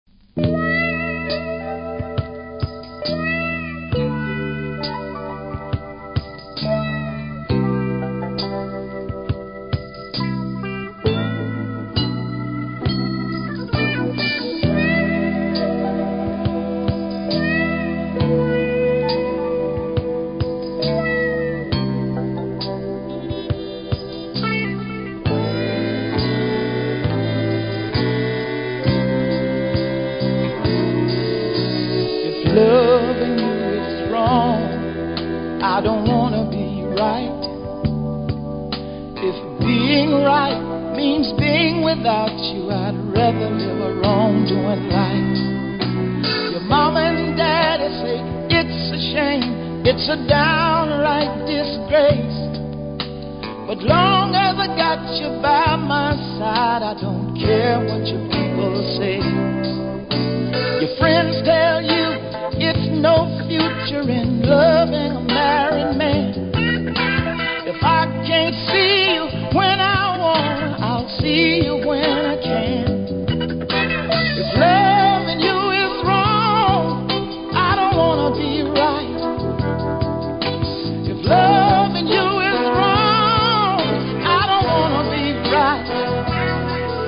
ジャマイカのソウル・タイム定番♪